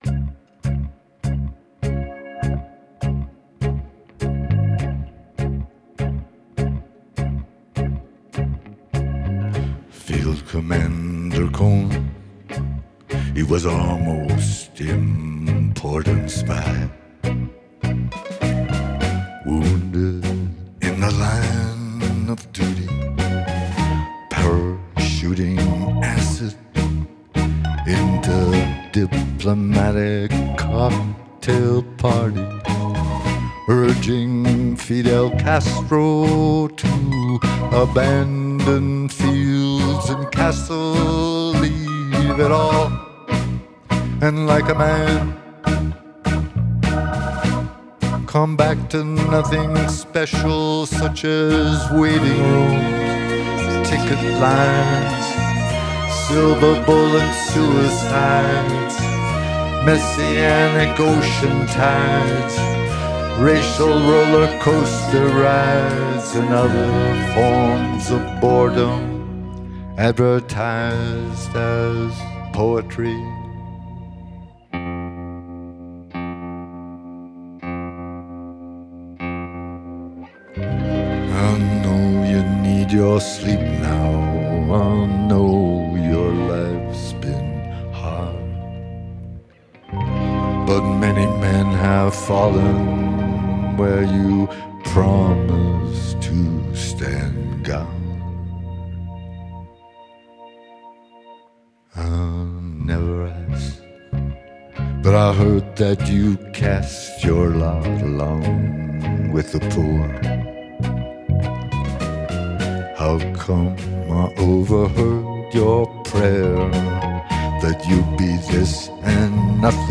Genre: Folk, Blues, Singer-Songwriter
Live at Denver Soundcheck, 2012